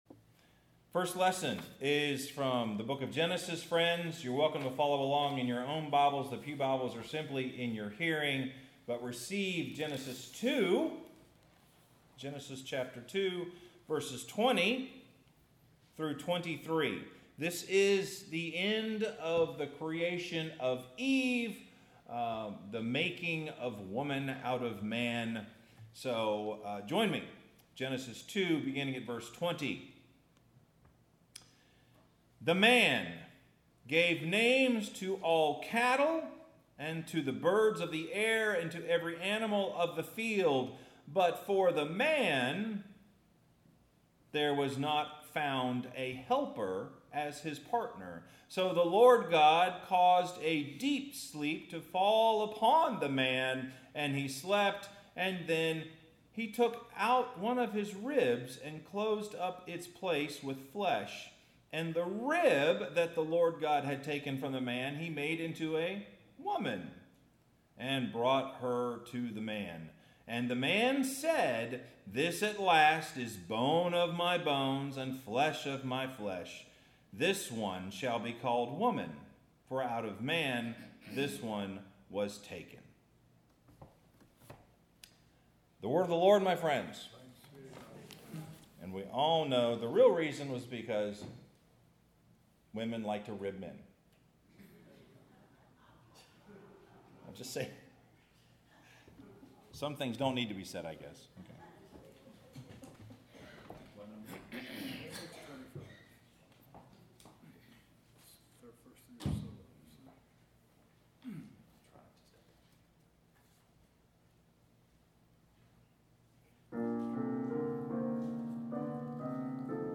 Sermon – Being Baptized Together